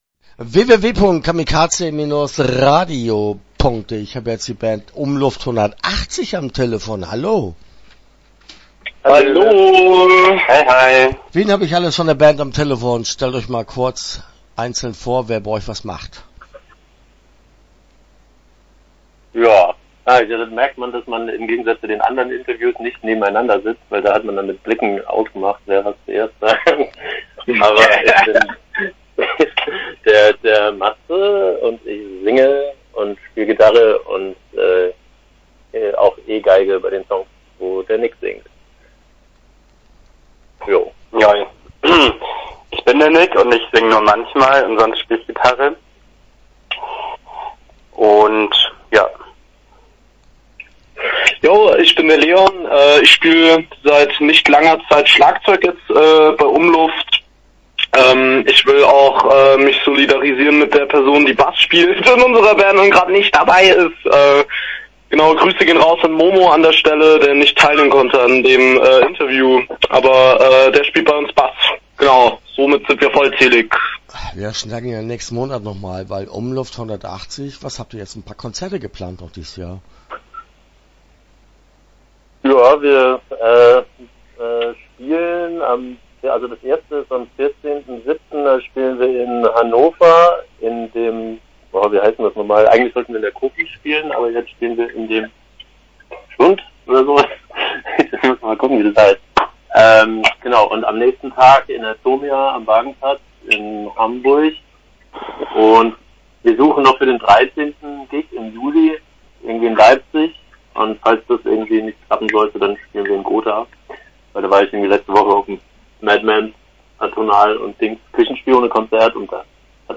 Start » Interviews » Umluft 180°